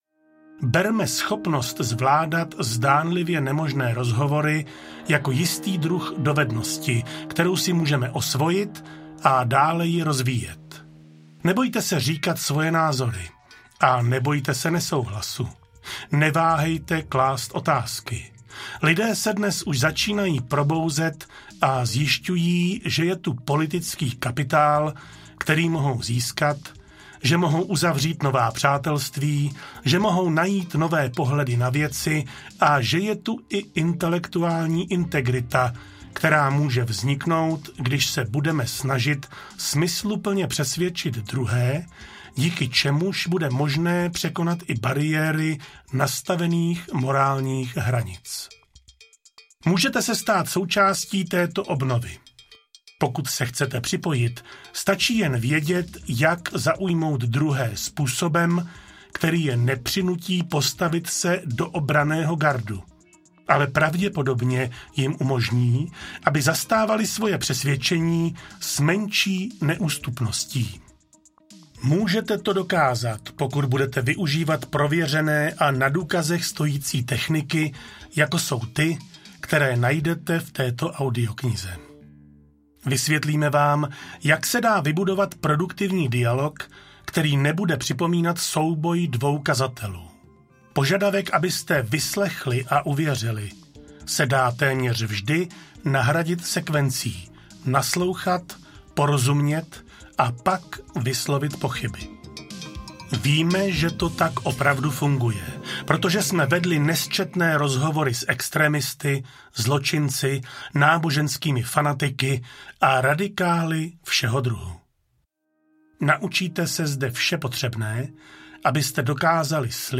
Jak vést (zdánlivě) nemožné rozhovory audiokniha
Ukázka z knihy